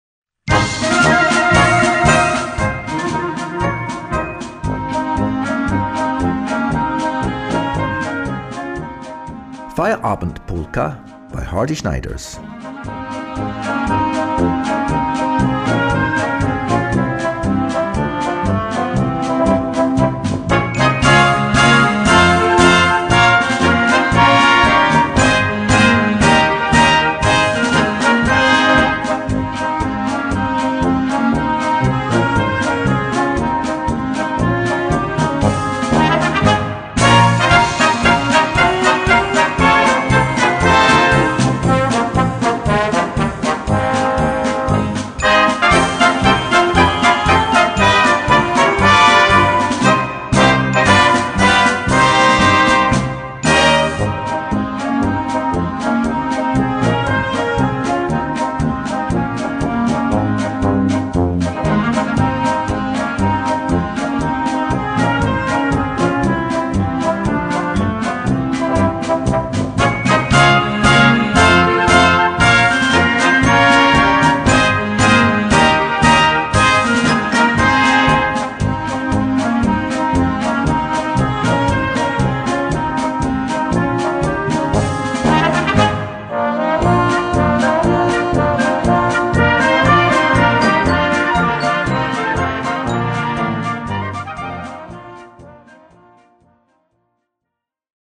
Gattung: Polka
A4 Besetzung: Blasorchester Zu hören auf